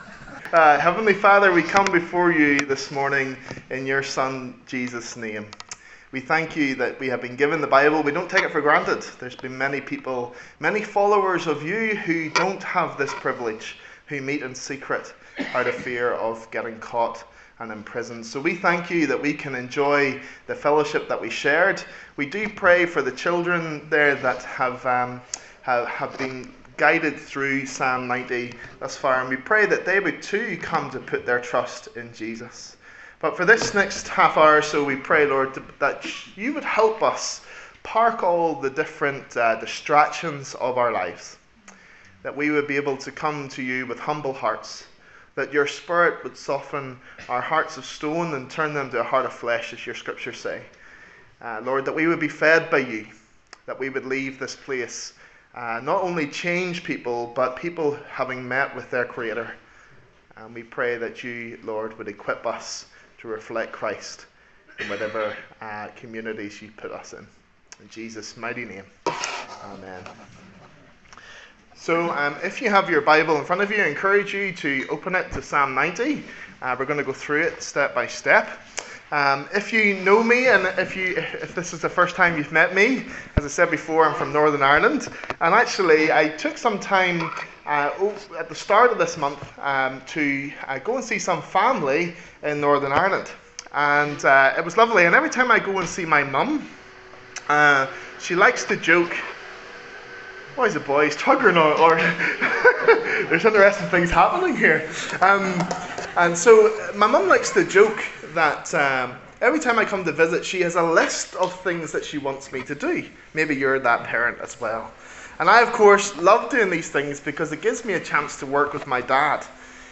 A sermon on the book of Psalms
Psalms Passage: Psalm 90 Service Type: Sunday Service